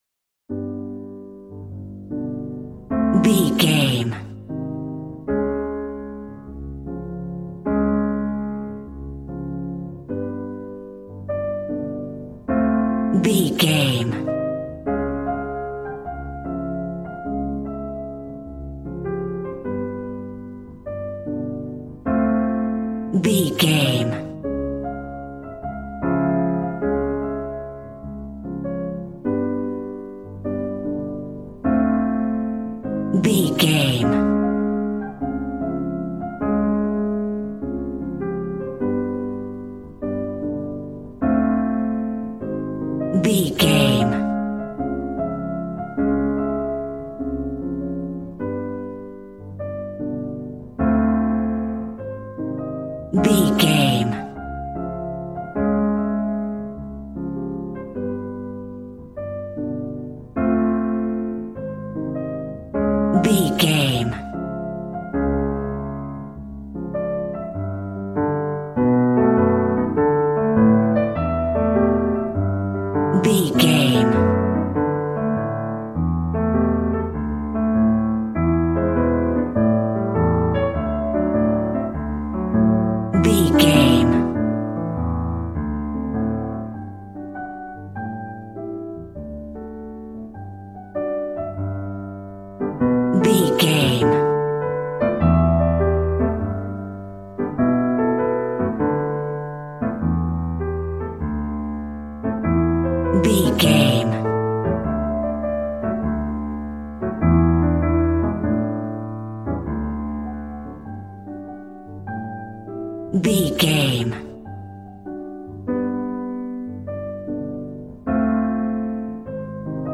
Ionian/Major